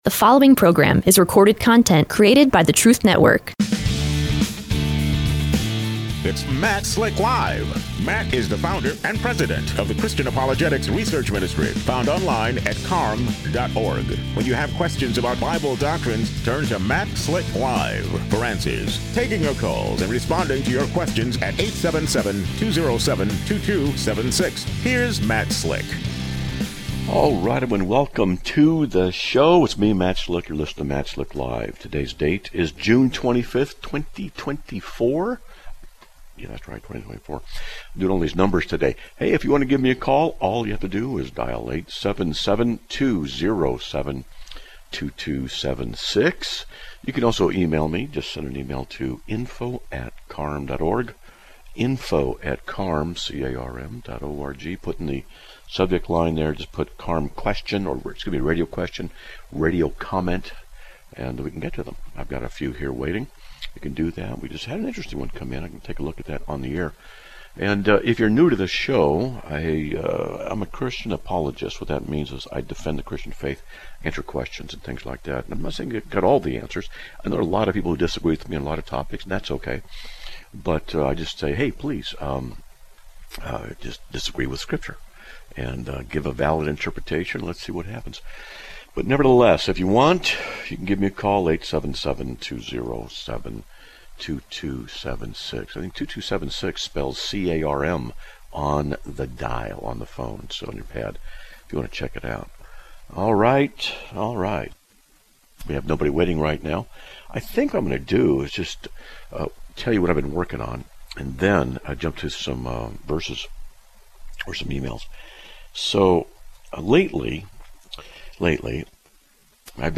Live Broadcast